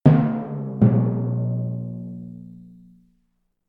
Failure Drum Sound Effect 3
cartoon drum error fail failure funny game-over humorous sound effect free sound royalty free Funny